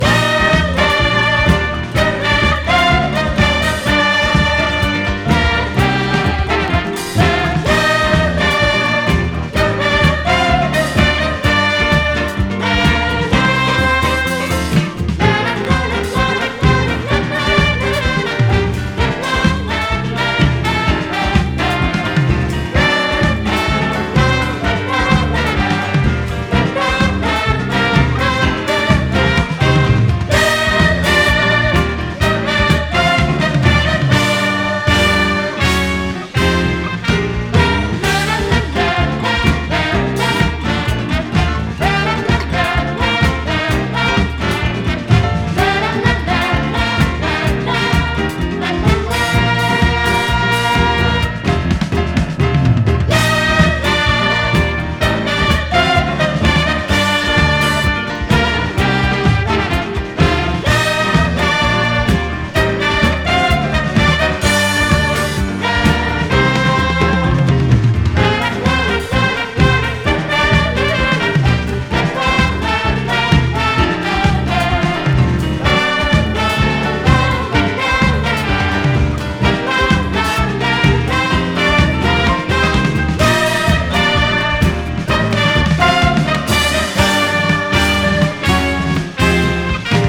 JAZZ / DANCEFLOOR / JAZZ FUNK / FUSION / A.O.R.
アーベインなジャズ・ファンクA.O.R.インスト！
洗練されたアーバン・フュージョン/ジャズ・ファンクA.O.R.インストを展開しています！